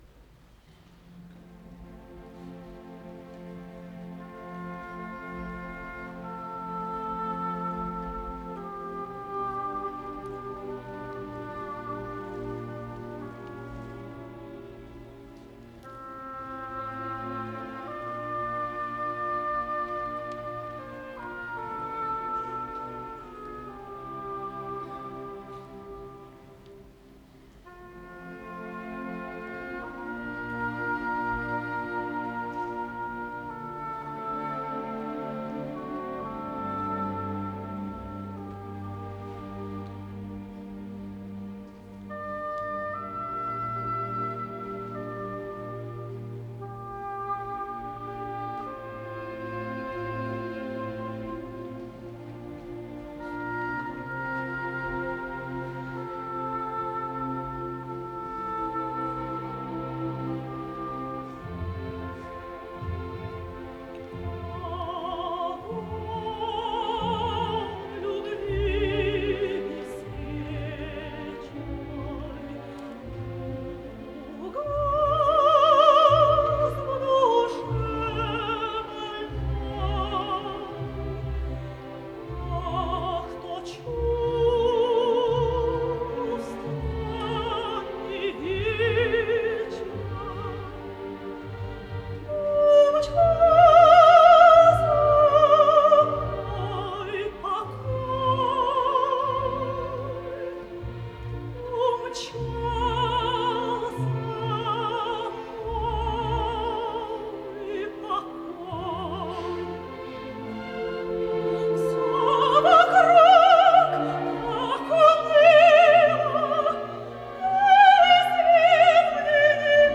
Драматическая легенда для солистов, хора и оркестра, соч. 24 Гектор Берлионов